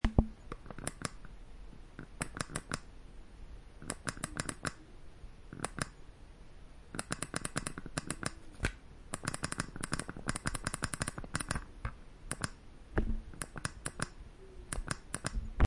Mouse clicks
mouse-clicks-33100.mp3